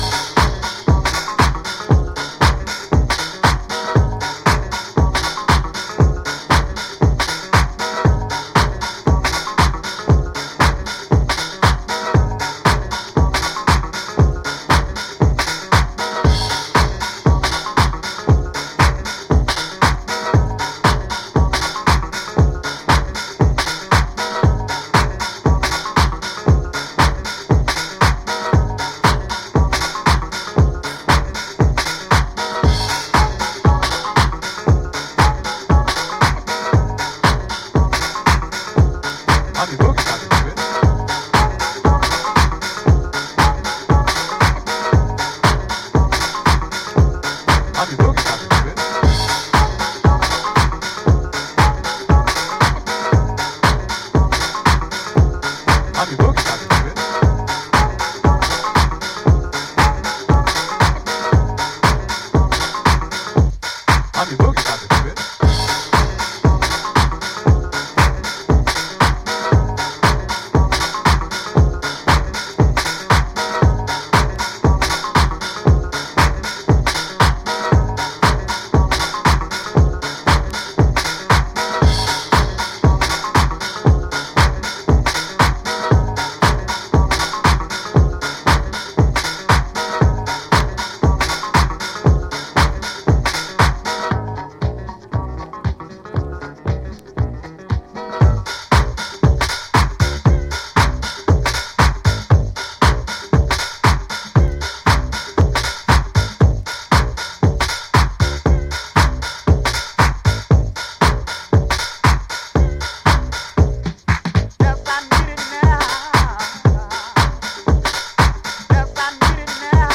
Classy 1994 NY House gem carefully re-issued